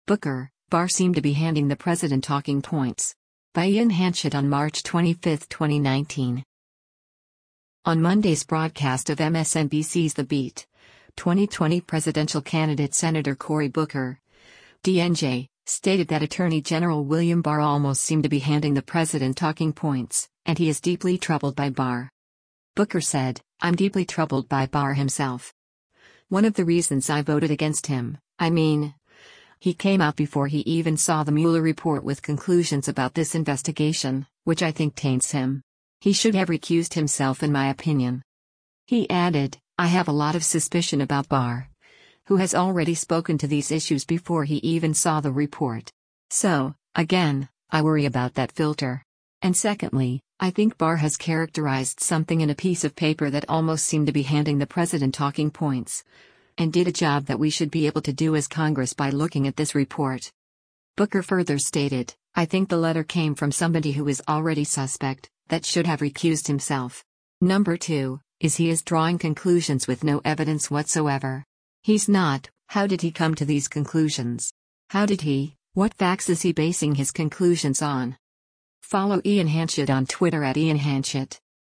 On Monday’s broadcast of MSNBC’s “The Beat,” 2020 presidential candidate Senator Cory Booker (D-NJ) stated that Attorney General William Barr “almost seemed to be handing the president talking points,” and he is “deeply troubled” by Barr.